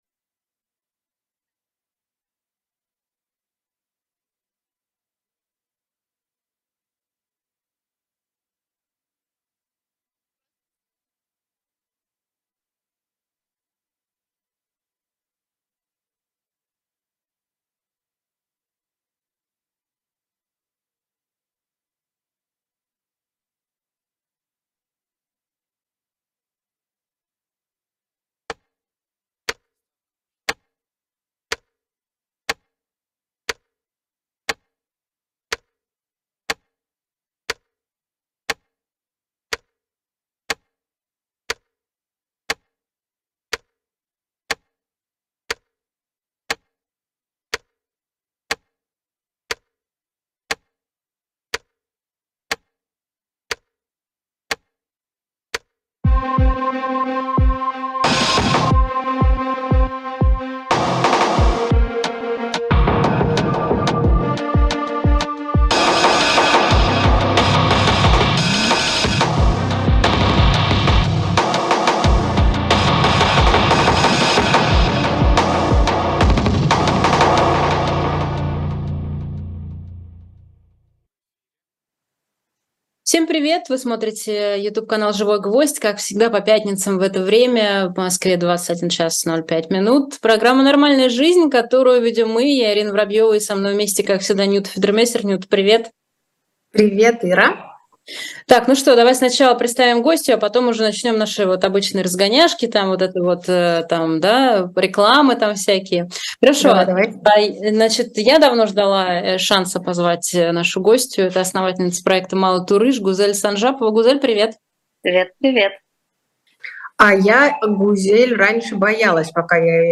Ведущие: Ирина Воробьёва и Нюта Федермессер.